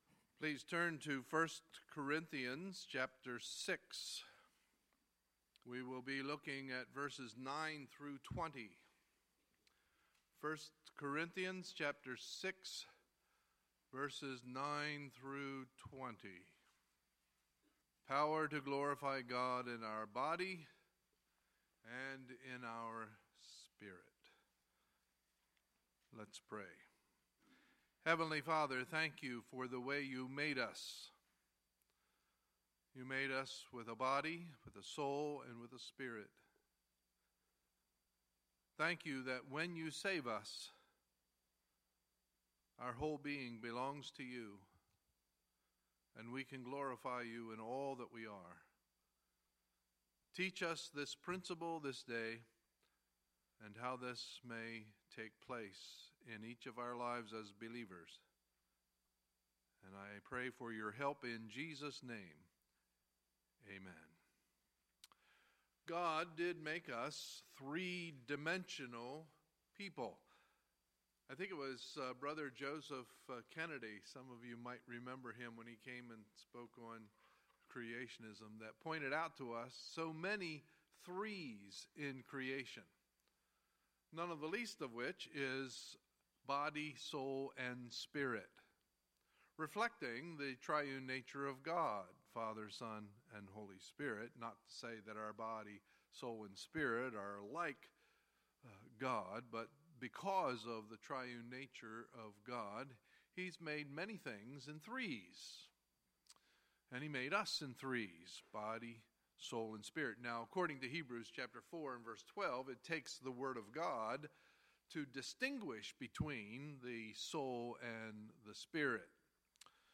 Sunday, March 5, 2017 – Sunday Morning Service
Sermons